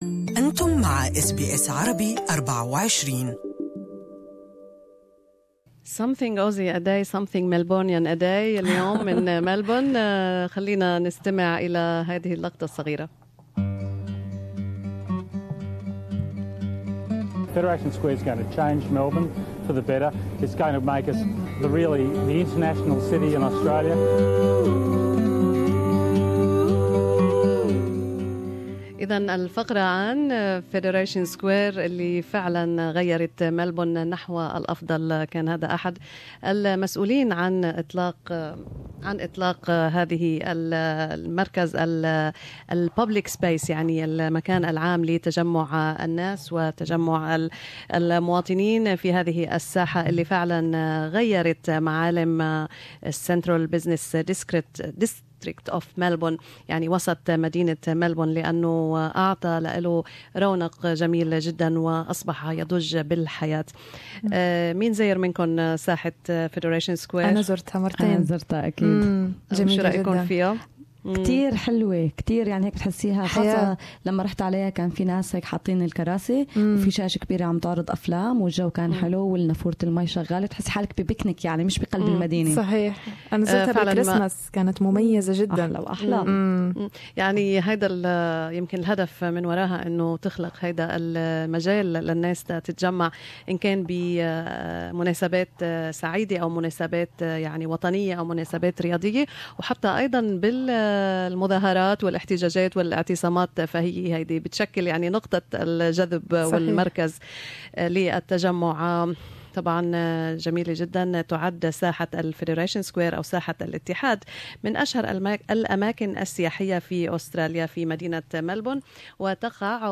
Federation Square in Melbourne is the most visited place in the city. Report in Arabic